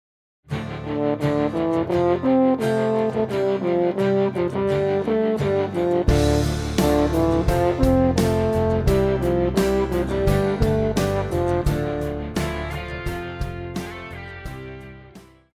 Pop
French Horn
Band
Instrumental
Punk
Only backing